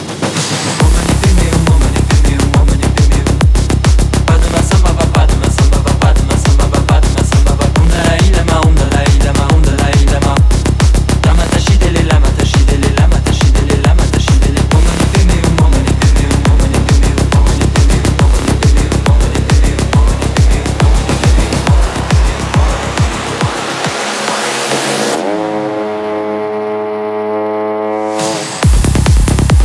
Стиль: progressive psy-trance Ура!